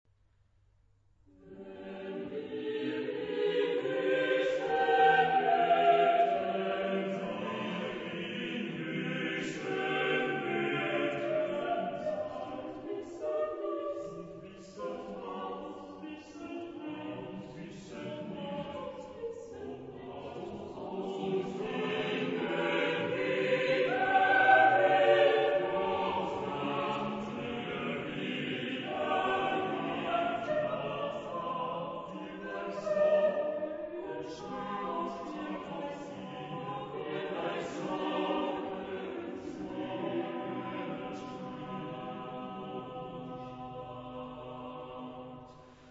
Epoque: 19th century  (1850-1899)
Genre-Style-Form: Motet ; Sacred ; Romantic
Mood of the piece: andante
Type of Choir: SATB + SATB  (8 double choir OR mixed voices )
Tonality: C minor
sung by Kammerchor Stuttgart conducted by Frieder Bernius